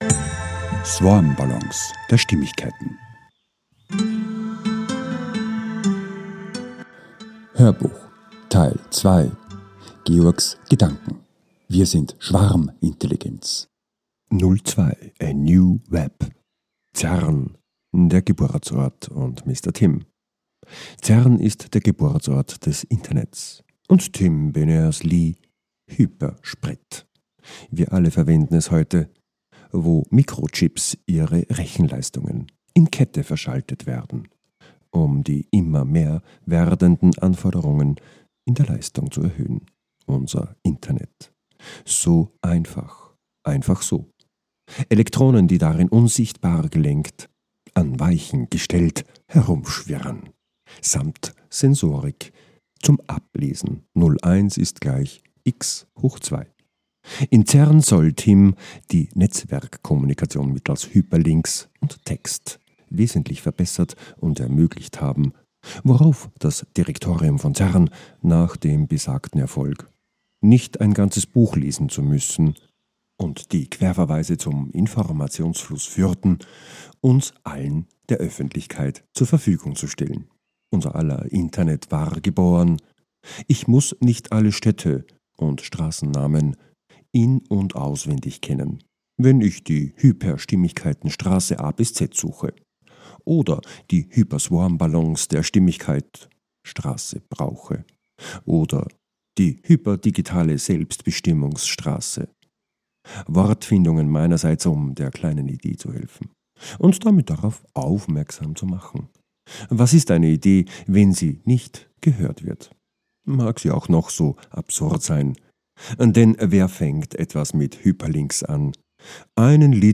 HÖRBUCH Teil 2 - 02 - WIR SIND SCHWARMINTELLIGENZ 2 - A NEW WEB - CERN, HYPERLINK & Mr. Tim